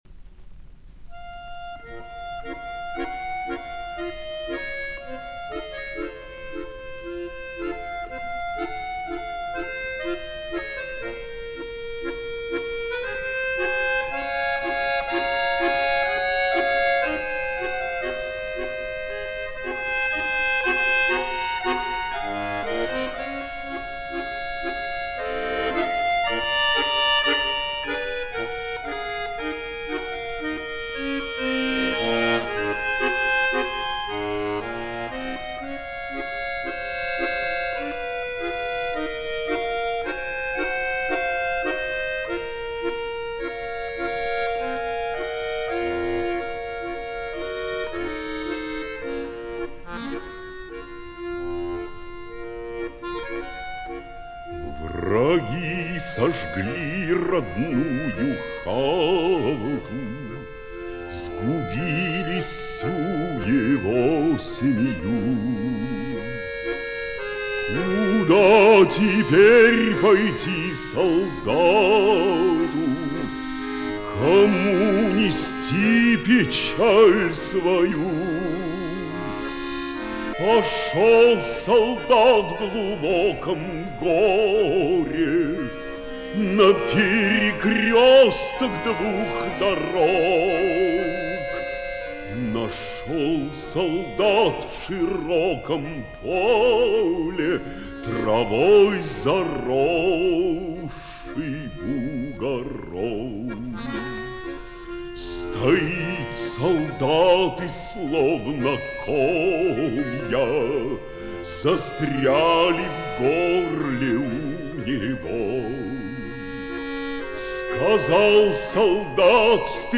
На смену по качеству. На пластинке озаглавлена так.
баян